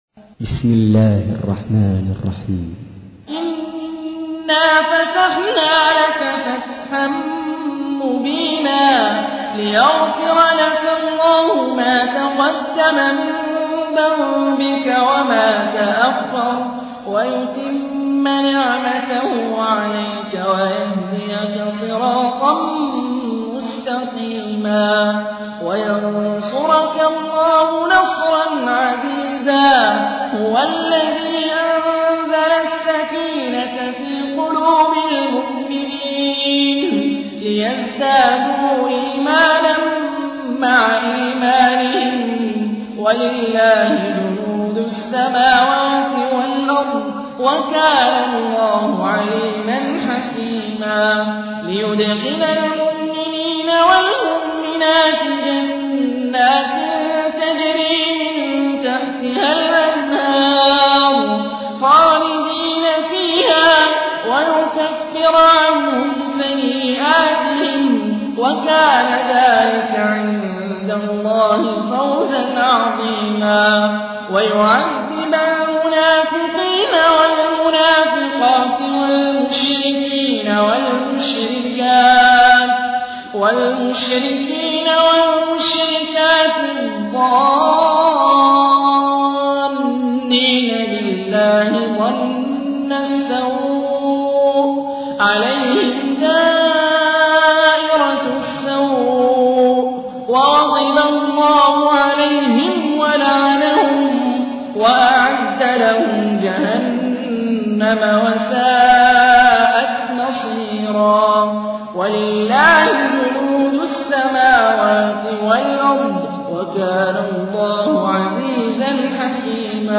تحميل : 48. سورة الفتح / القارئ هاني الرفاعي / القرآن الكريم / موقع يا حسين